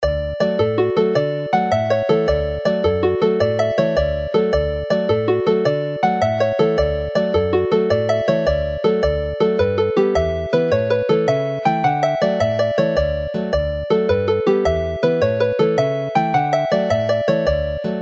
Alawon traddodiadol Cymreig - Set Hela'r Sgyfarnog, gyda Cainc Dafydd ap Gwilym a Ty a Gardd